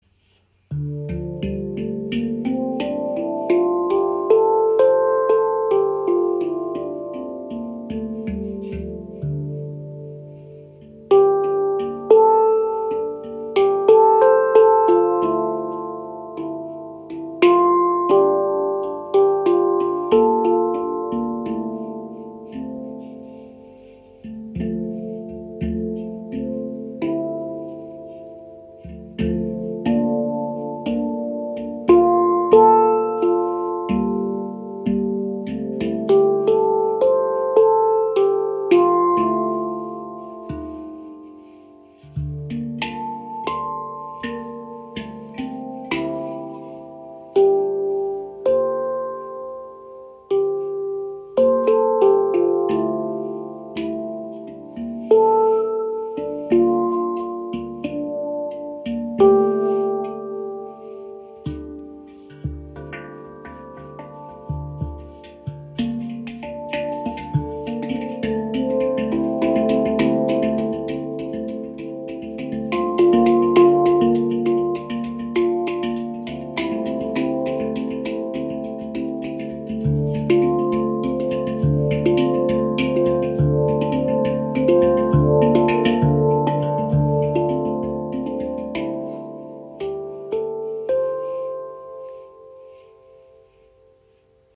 Klangbeispiel
Diese ShaktiPan aus Ember Steel hat mit D Kurd eine der beliebtesten Stimmungen.
Sie bietet spannende Basseffekte, sowie die Möglichkeit melodiöse und perkussive Elemente wunderbar zu verbinden.
Alle Klangfelder sind sehr gut gestimmt und lassen sich auch mit fortgeschrittenen Spieltechniken anspielen.
Handpans mit einer Moll-Stimmung wie z.B. Kurd klingen mystisch, verträumt, vielleicht auch etwas melancholisch.